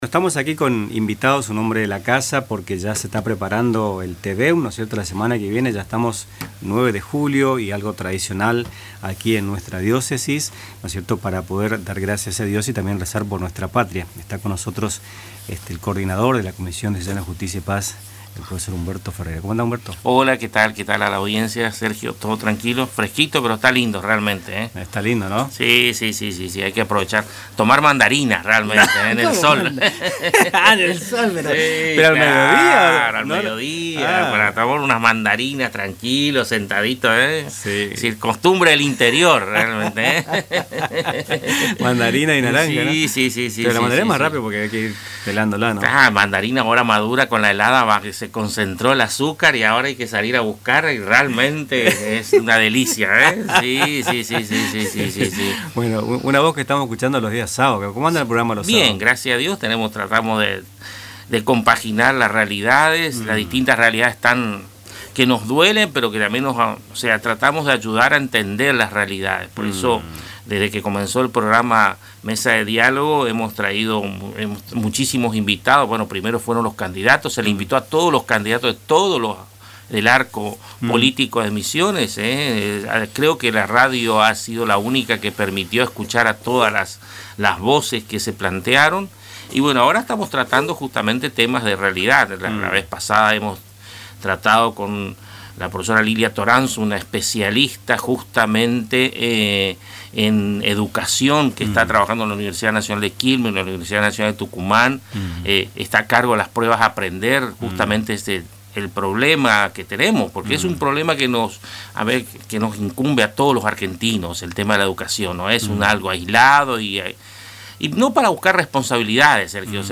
En diálogo con el programa “Nuestras Mañanas”
Durante la entrevista, el docente también compartió una mirada crítica sobre la realidad argentina, especialmente en lo que refiere a la pobreza, la falta de políticas de Estado, la crisis educativa y el rol de la dir